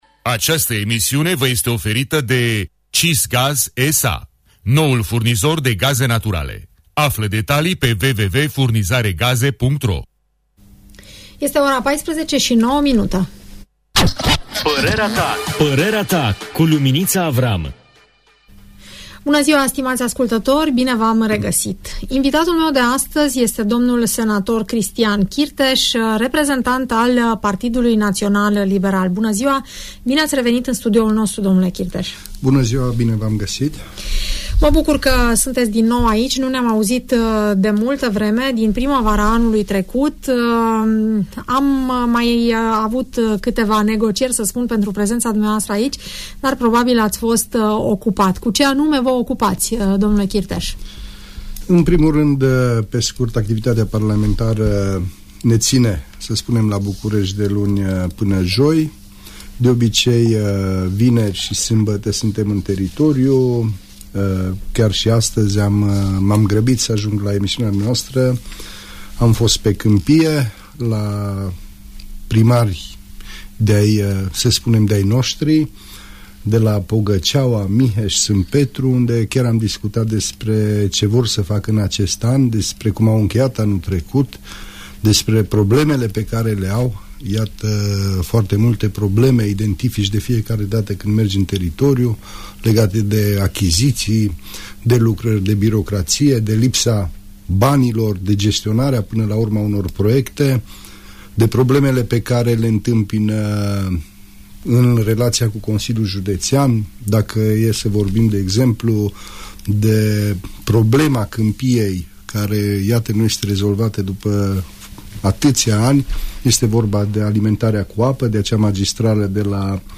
Domnul Cristian Chirteș, senator al Partidului Național Liberal, își prezintă activitatea și răspunde întrebărilor ascultătorilor, în emisiunea „Părerea ta” de la Radio Tg Mureș